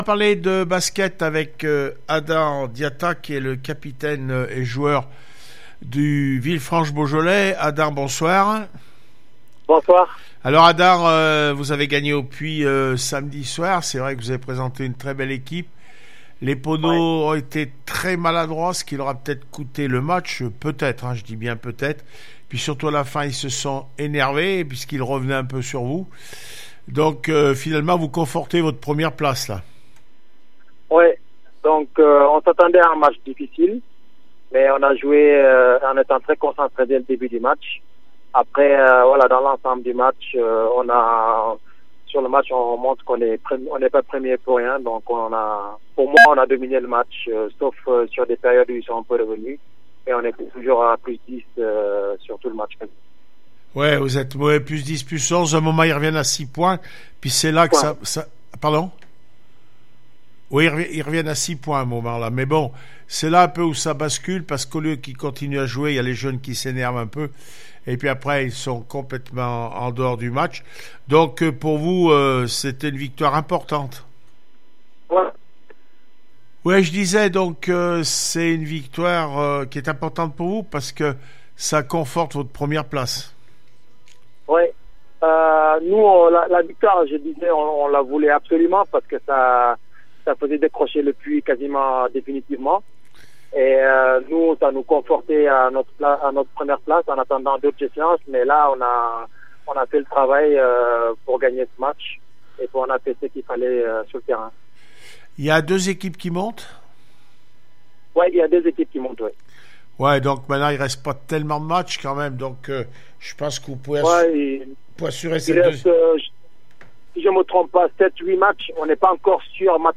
basket aura regionale 2 masculine asmb le puy 62-70 villefranche en beaujolais réac après match 090320